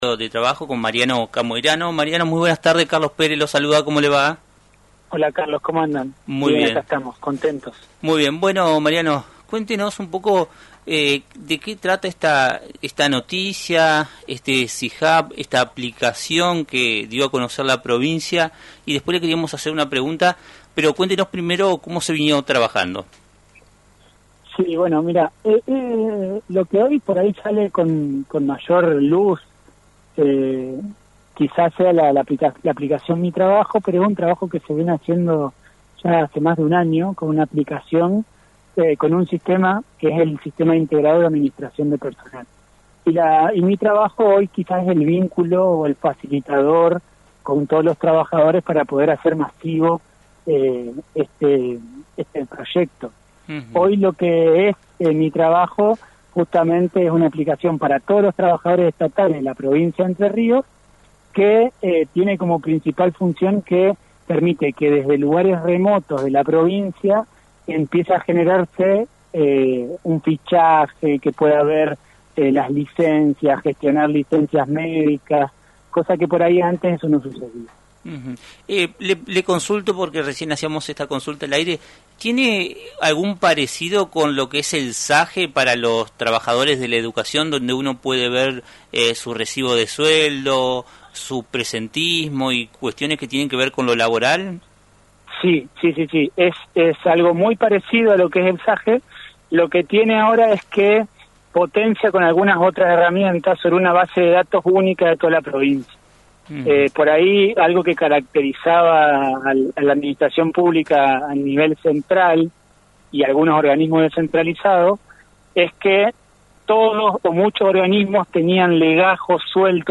La provincia de Entre Ríos lanzó “Mi Trabajo”, una innovadora aplicación destinada a los trabajadores estatales, según se dio a conocer en una entrevista realizada en el programa radial Puntos Comunes a Mariano Camoirano, Secretario de Trabajo y Seguridad Social de Entre Ríos.
Mariano Camoirano- Secretario de Trabajo y Seguridad Social de Entre Ríos.